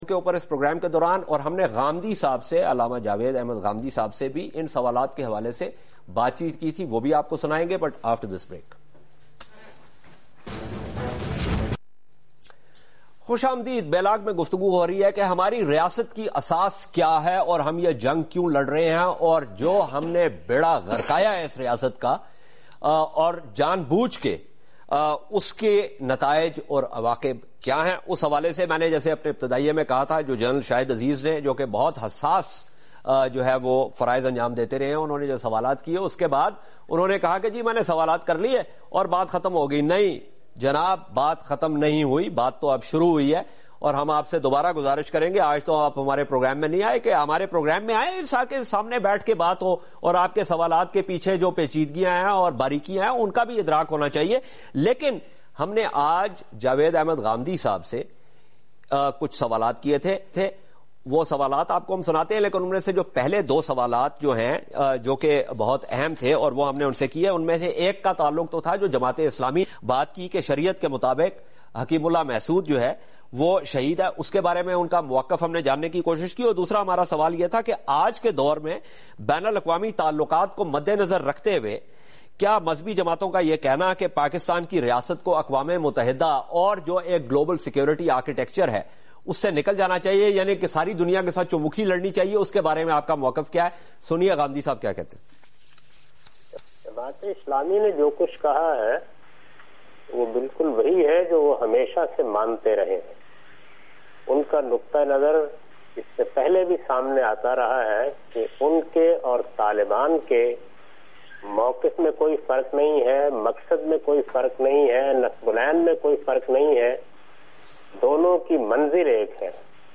Category: TV Programs / Questions_Answers /
Javed Ghamidi discuss about Taliban and Jamat e Islami on Capital Tv in talk show